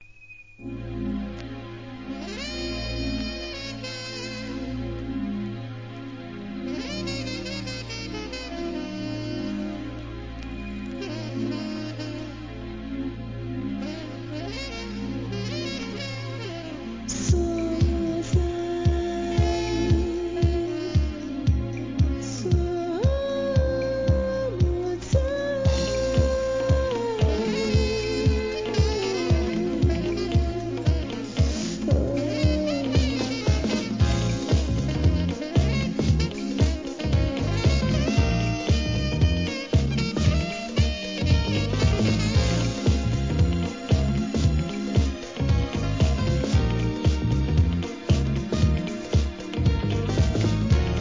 HIP HOP/R&B
1996年、両面ともに洒落オツなJAZZYトラックでオススメ!!